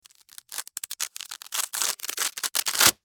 Velcro Rip
Velcro_rip.mp3